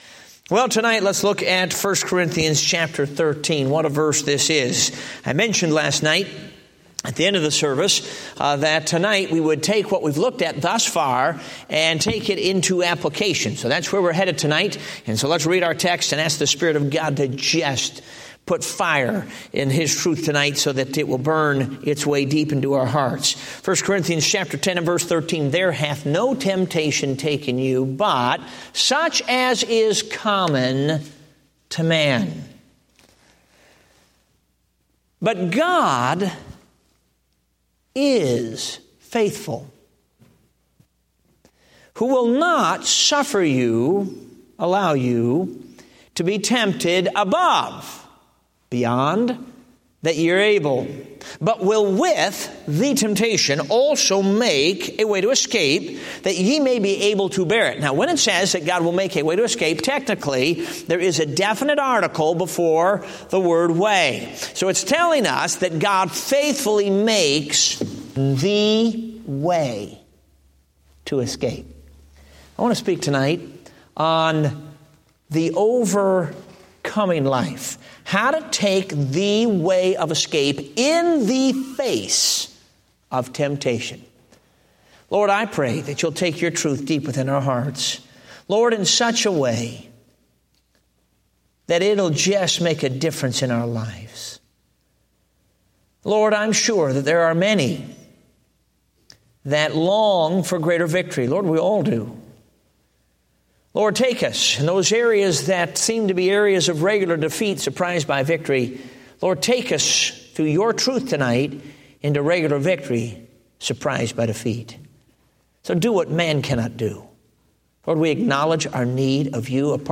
Date: September 9, 2015 (Revival Meeting)